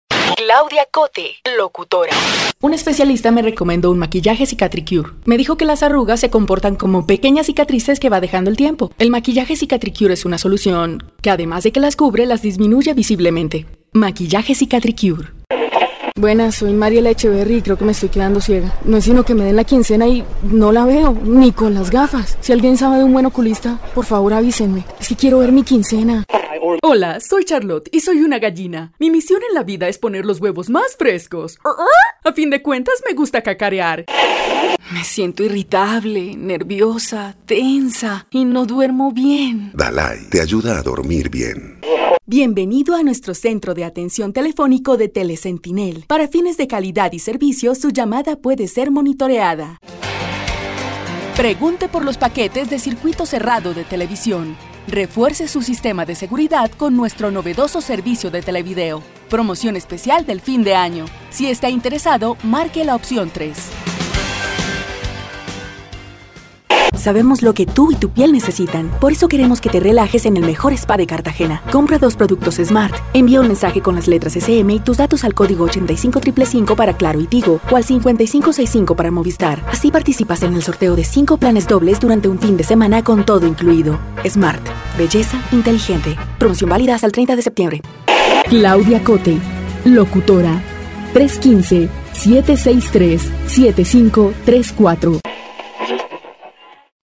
Poseo una voz versátil para locución publicitaria y doblaje. Mi rango de voz abarca desde niños y niñas pequeños hasta mujeres de mediana edad.
Sprechprobe: Werbung (Muttersprache):